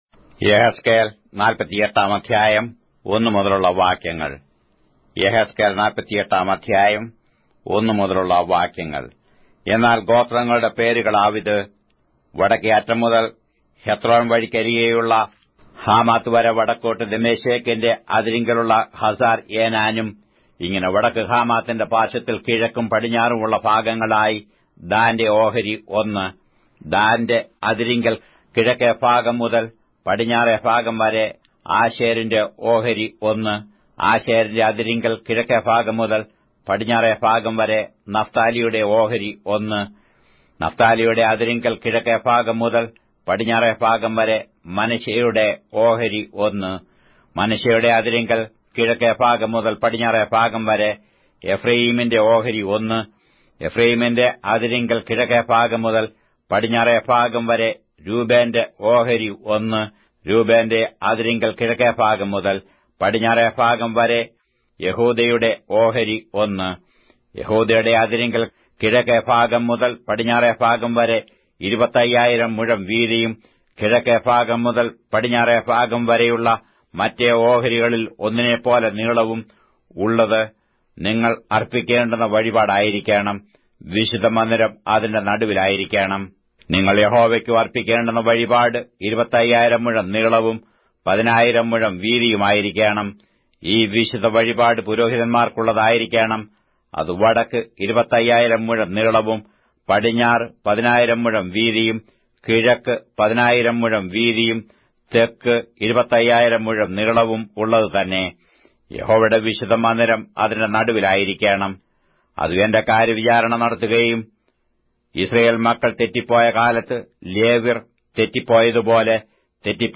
Malayalam Audio Bible - Ezekiel 4 in Irvmr bible version